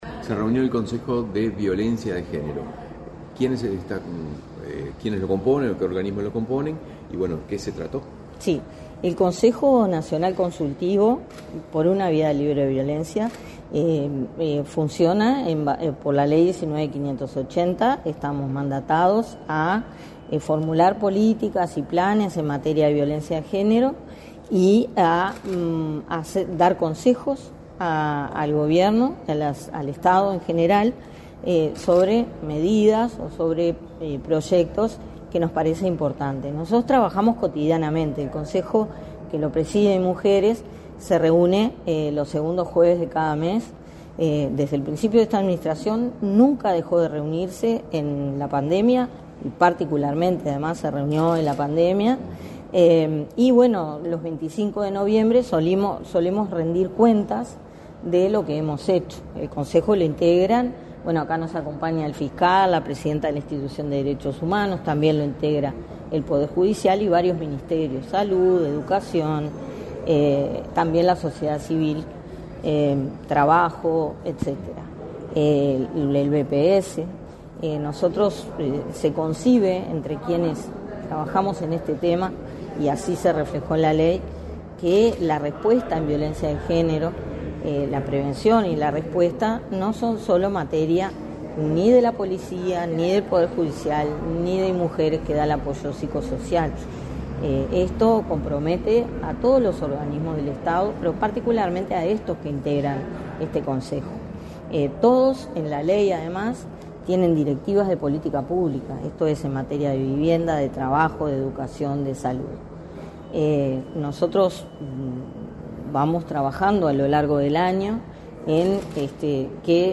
Declaraciones a la prensa de la directora del Inmujeres, Mónica Bottero
En ese marco, este 21 de noviembre el Consejo Nacional de Género, presidido por el Instituto Nacional de las Mujeres (Inmujeres), presentó el informe a la vicepresidenta de la República en ejercicio de la Presidencia, Beatriz Argimón. Tras el evento, la directora del Inmujeres, Mónica Bottero, realizó declaraciones a la prensa.